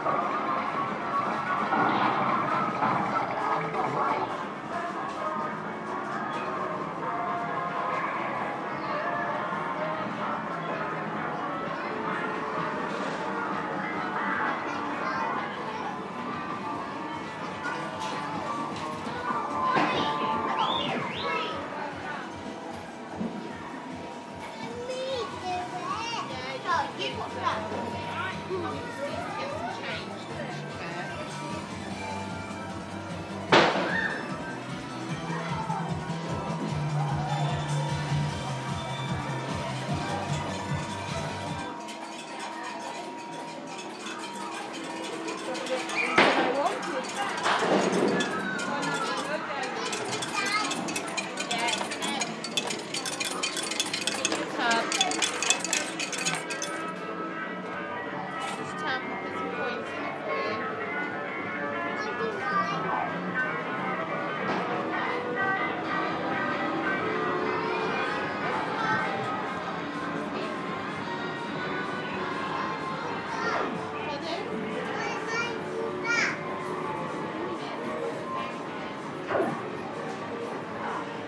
Arcade sounds, Sea Palling, Norfolk